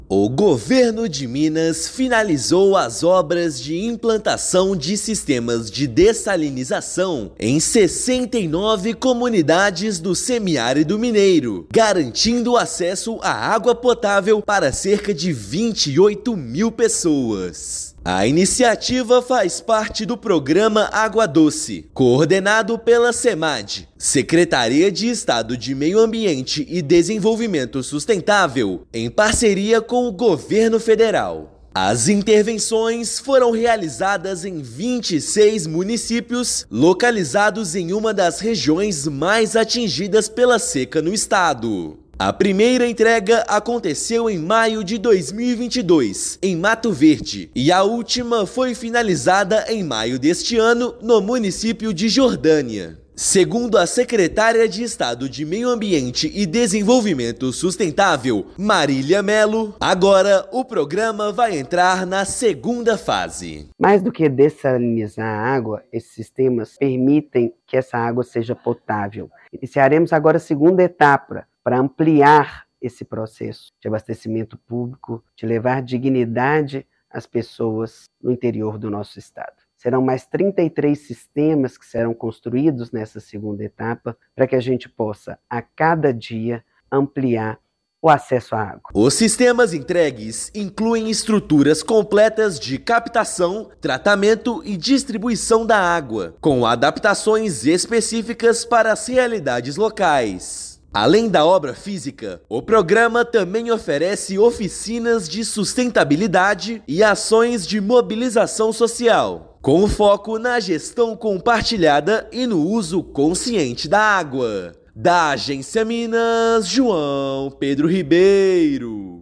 [RÁDIO] Minas Gerais conclui obras de dessalinização e leva água potável a 28 mil pessoas no semiárido
Comunidades rurais foram atendidas em 26 municípios pelo Programa Água Doce, que entra em nova fase com mais investimentos. Ouça matéria de rádio.